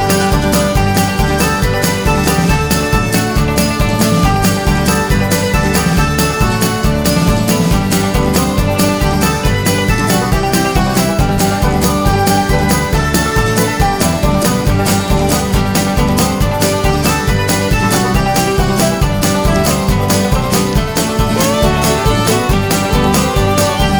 no Backing Vocals Irish 4:06 Buy £1.50